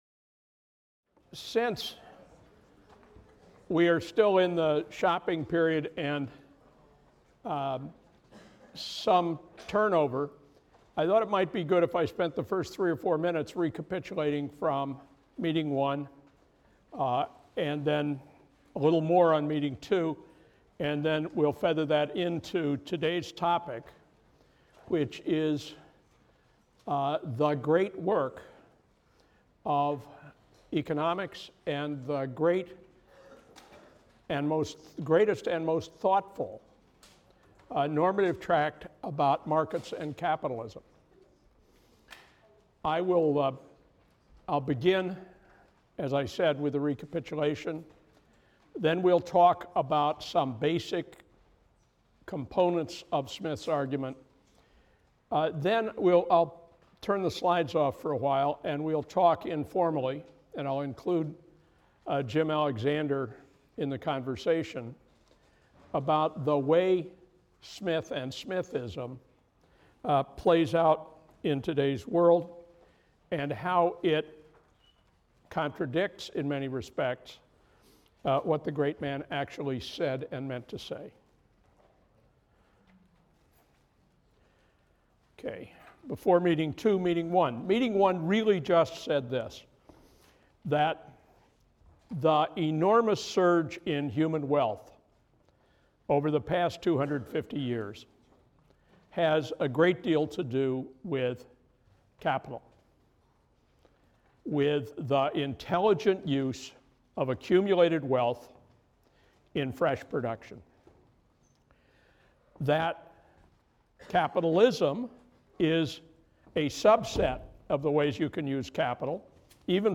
PLSC 270 - Lecture 3 - Counting the Fingers of Adam Smith’s Invisible Hand | Open Yale Courses